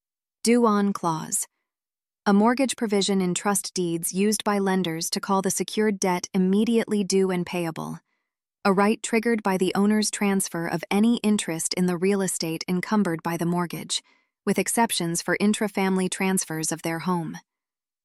Listen to the terms you’ll need to remember most with an audio reading of definitions while you think through them.